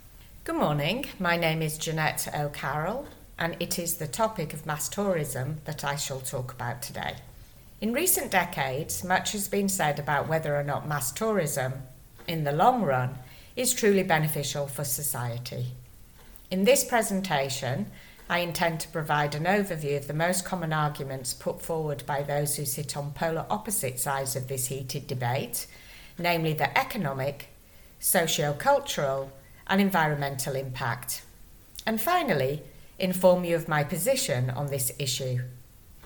• Exam-ready recorded monologue (MP3)
eoi-c2-mass-tourism-preview.mp3